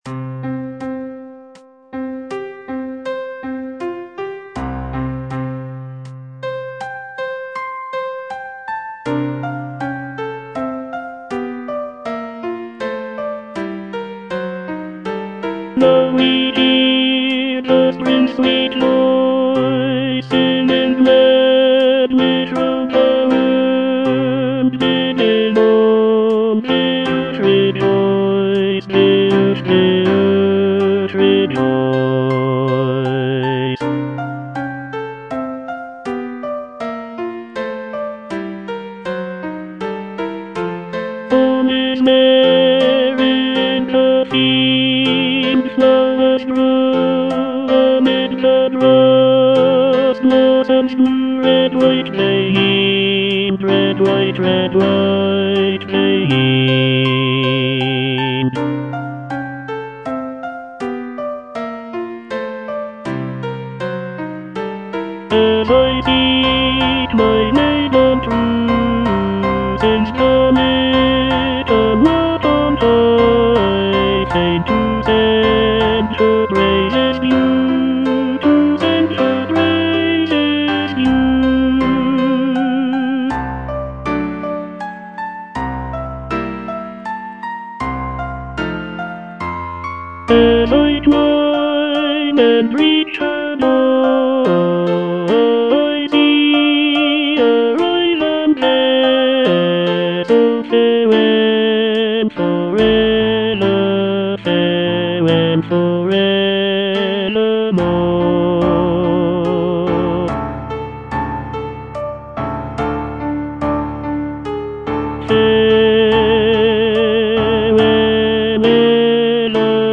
Tenor (Voice with metronome) Ads stop